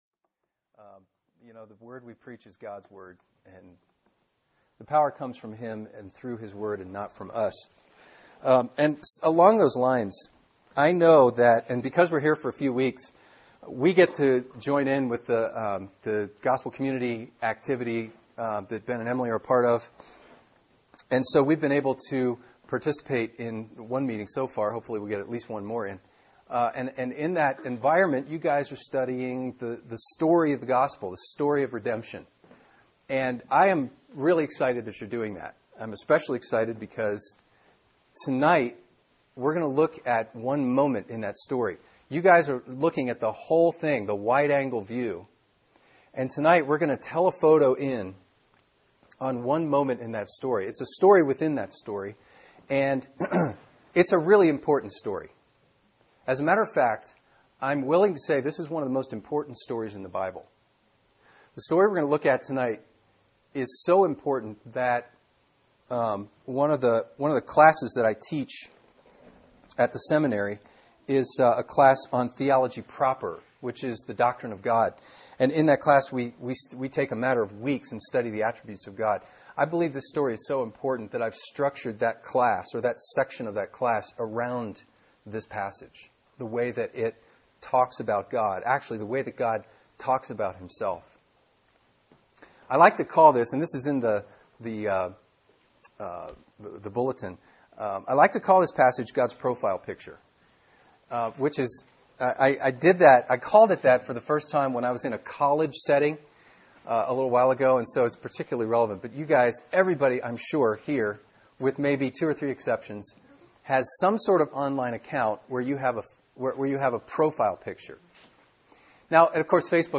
Sermon: Exodus 33 & 34 “God’s Profile Picture” | Cornerstone Church - Jackson Hole